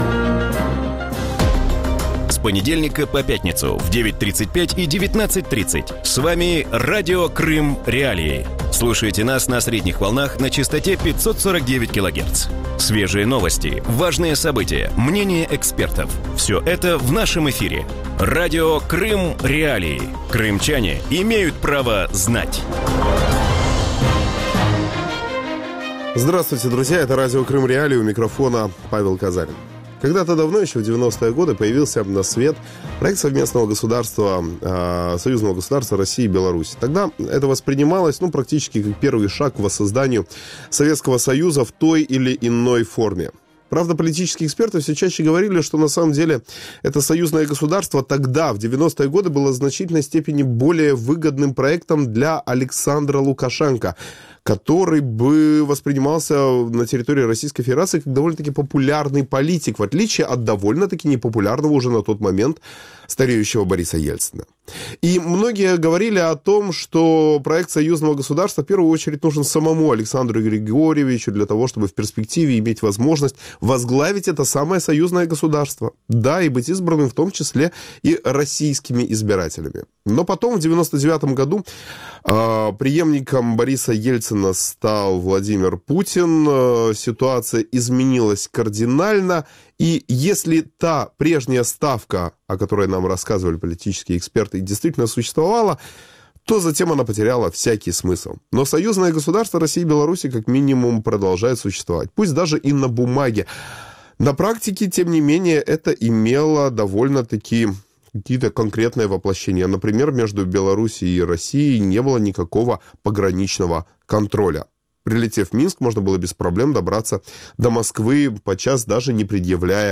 В вечернем эфире Радио Крым.Реалии говорят о похолодании российско-белорусских отношений и создании зон таможенного контроля на границе. Почему Россия решила создать таможенную зону с Беларусью, станет ли Александр Лукашенко сближаться с Европейским союзом и останется ли у крымчан доступ к белорусским продуктам?